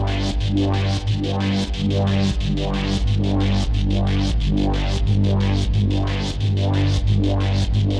Index of /musicradar/dystopian-drone-samples/Tempo Loops/90bpm
DD_TempoDroneE_90-G.wav